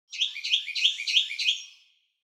دانلود آهنگ پرنده 19 از افکت صوتی انسان و موجودات زنده
دانلود صدای پرنده 19 از ساعد نیوز با لینک مستقیم و کیفیت بالا
جلوه های صوتی